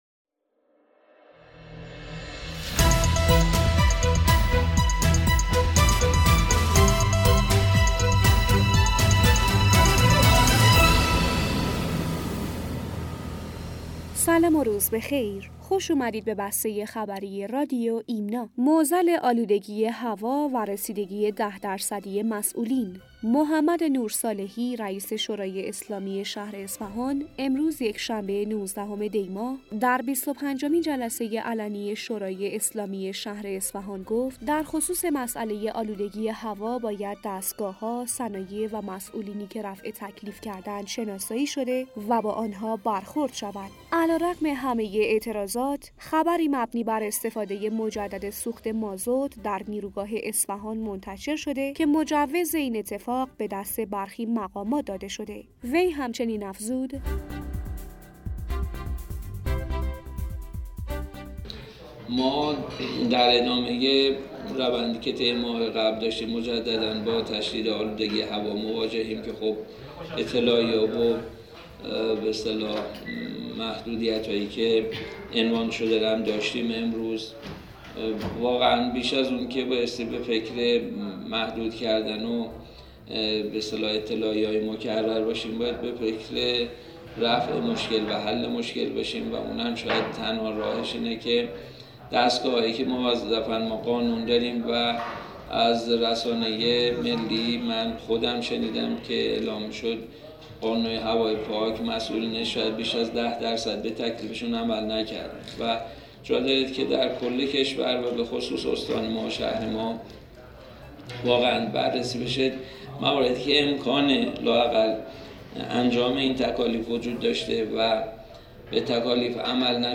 بسته خبری رادیو ایمنا/